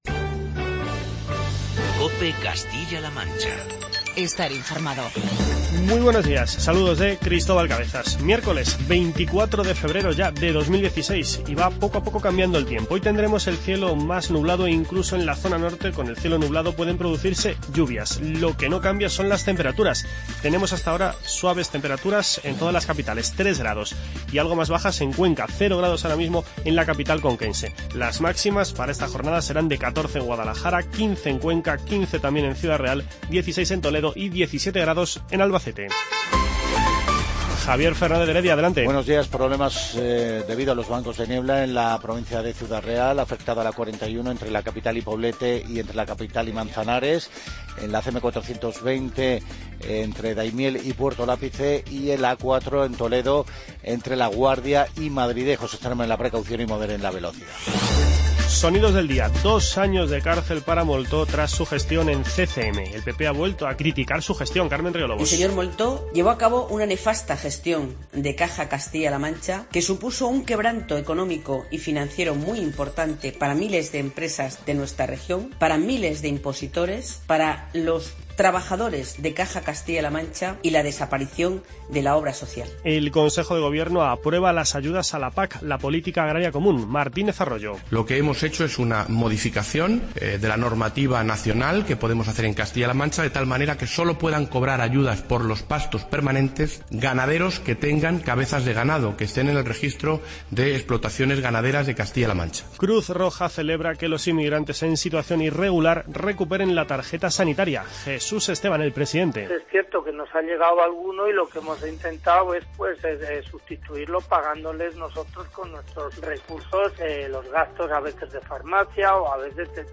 Informativo regional
Escuche las declaraciones de Carmen Riolobos, Francisco Martínez Arroyo y Jesús Esteban, entre otros.